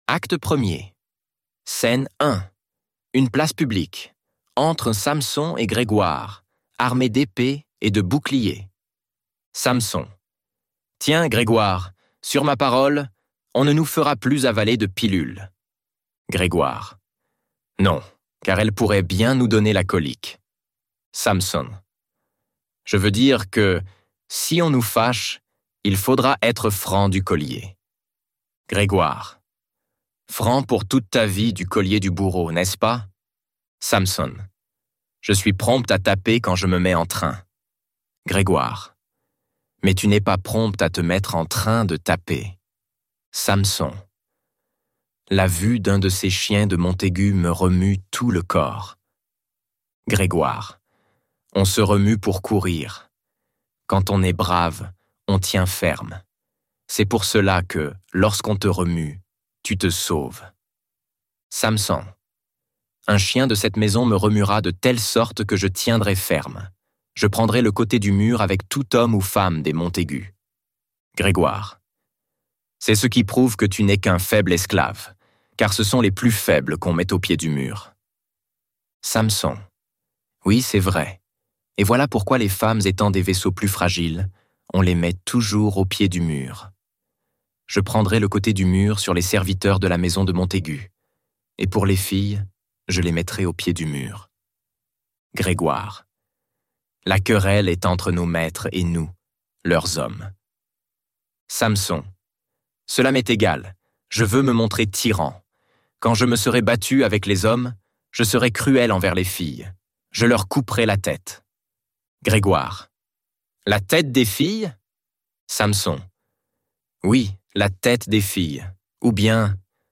Roméo et Juliette - Livre Audio